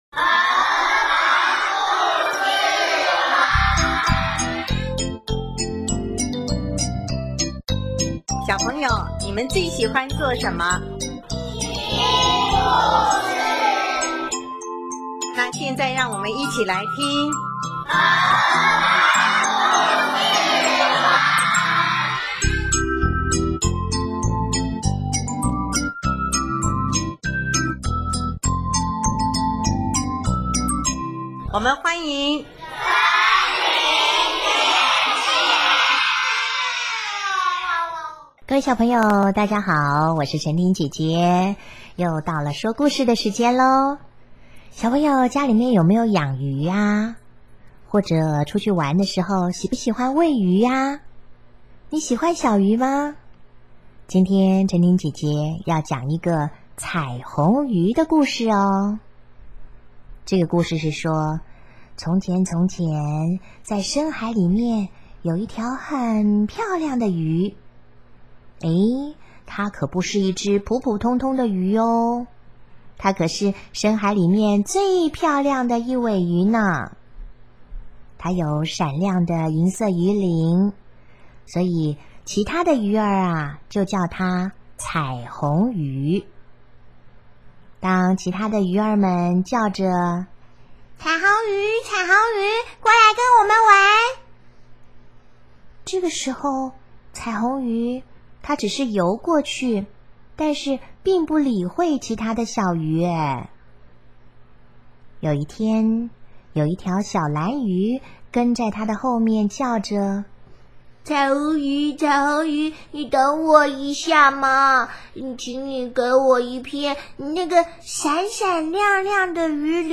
【儿童故事】|彩虹鱼